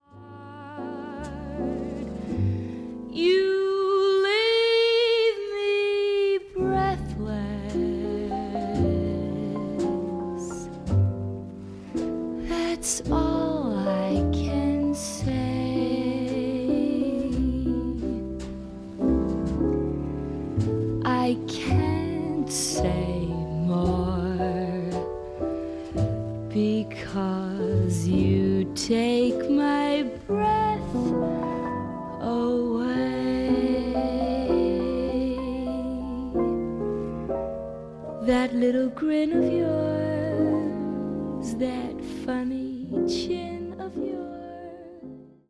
この時代の歌手ならではの明朗快活っぷりに、深く息をするようなデリケートな歌い方もできるアメリカのシンガー
恋をテーマにしたスロー〜ミディアム・テンポの曲でほぼ占められる本作。